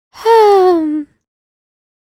shopkeep-sigh.wav